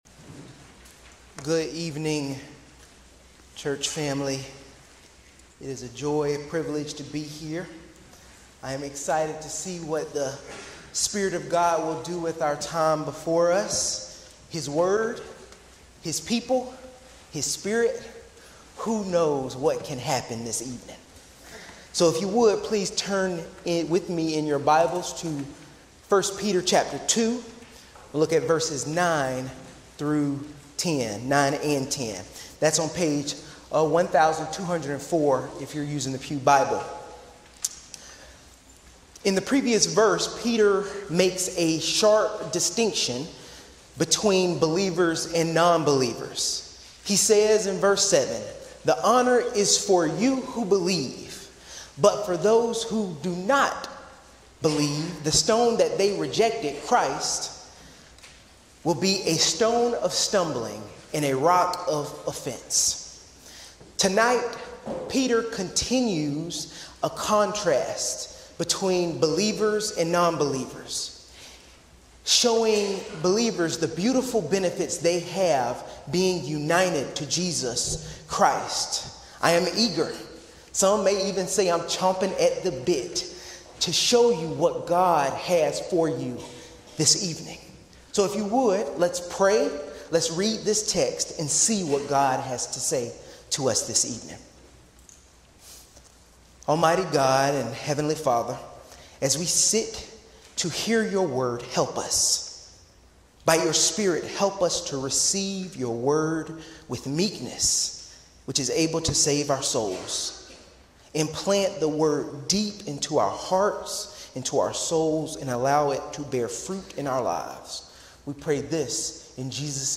10 most recent audio recordings of sermons from First Presbyterian Church, Augusta, GA.